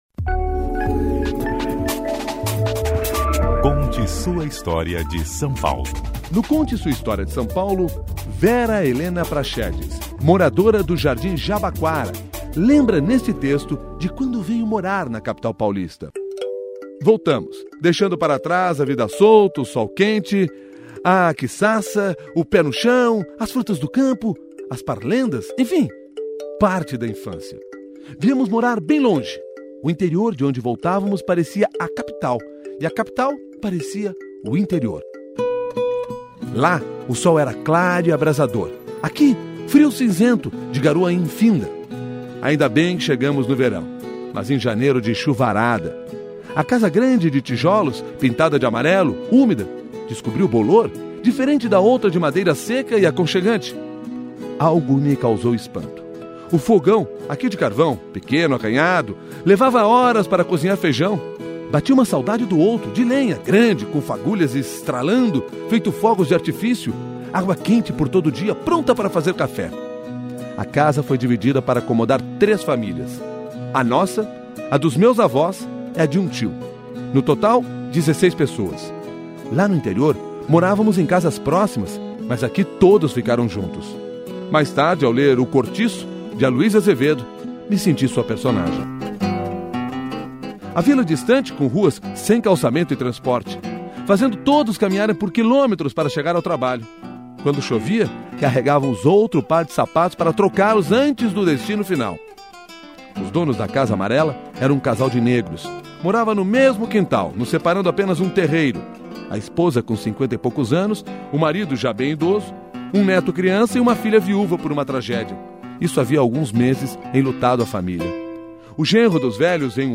sonorizado